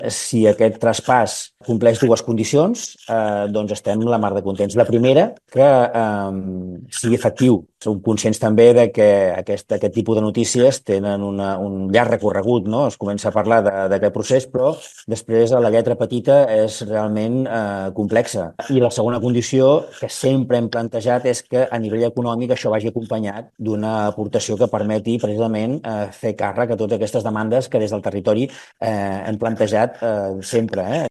Des del Maresme, el president del Consell Comarcal, el republicà Francesc Alemany, ho celebra però introdueix dos matisos: que serà un procés llarg i complex i que caldrà que s’acompanyi dels recursos econòmics que assegurin que es podran resoldre les deficiències del servei. Són declaracions a tvmataró: